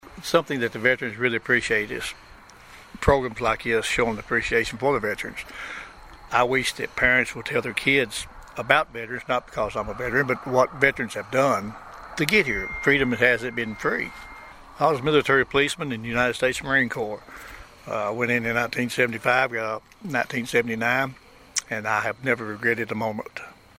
The Pleasant Grove Baptist Church located on Hopkinsville Road in Princeton recognized Veterans on Saturday night with a fish meal prepared by the church members.